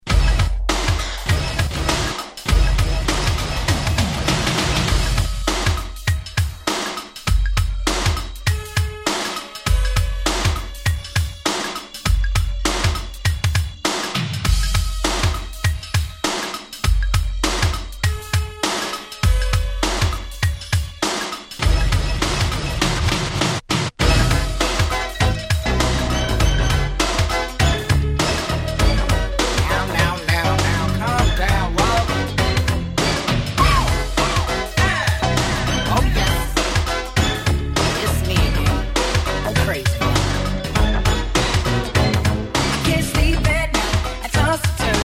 New Jack Swing !!